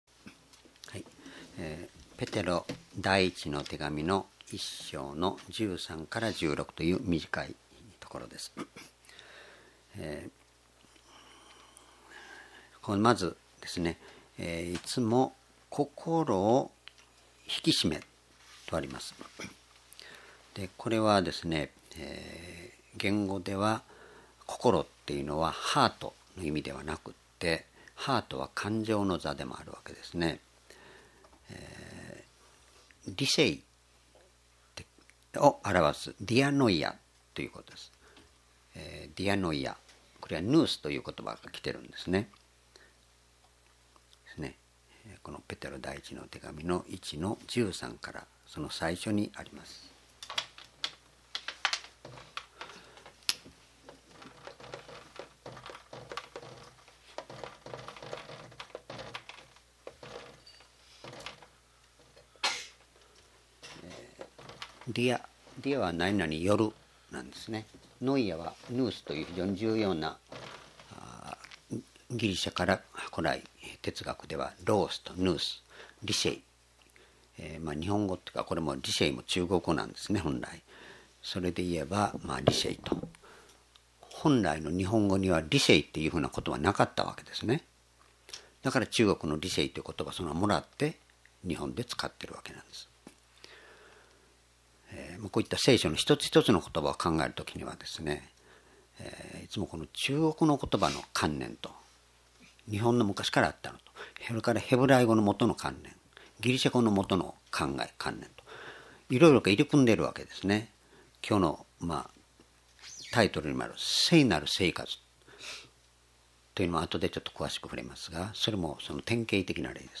講話 ペテロの手紙一 1章13-16｢聖なる生活をしよう｣